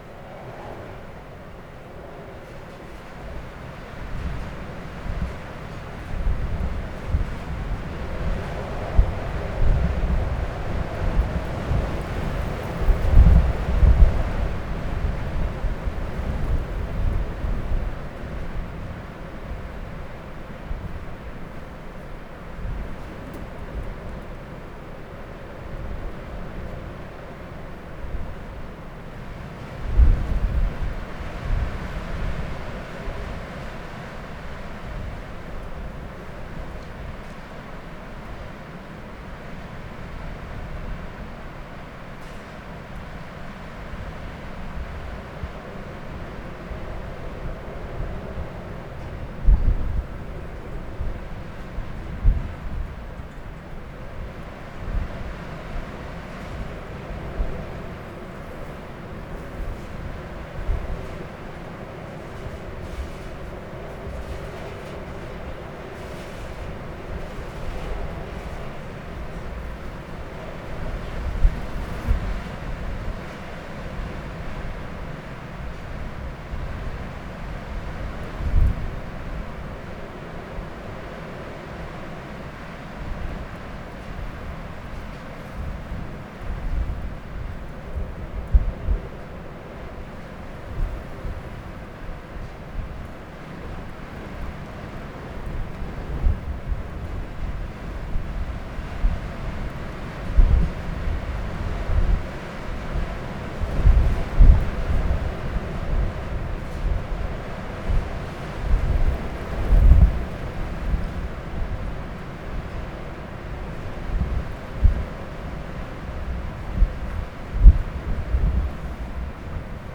desert.wav